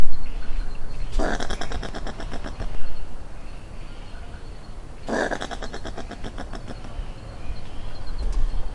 На этой странице собраны разнообразные звуки, издаваемые опоссумами: от защитного шипения до тихих щелчков.
Звук защитного опоссума при приближении